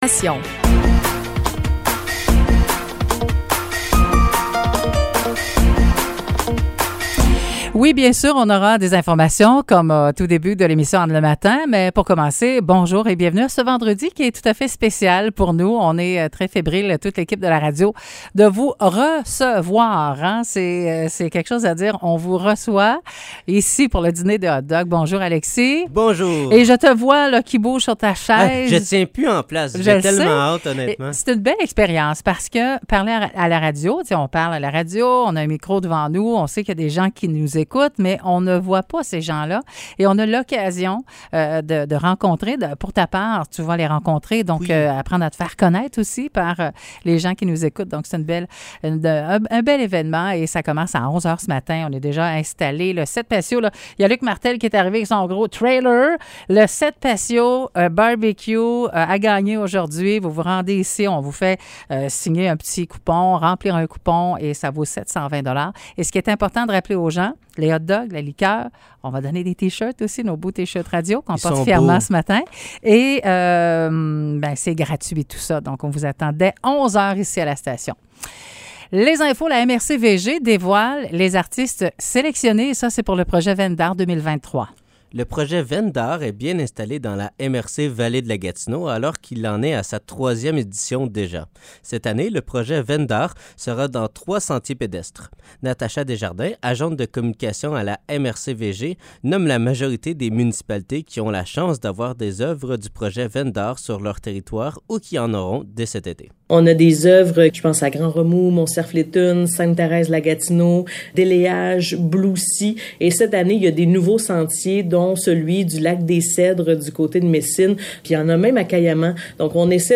Nouvelles locales - 9 juin 2023 - 9 h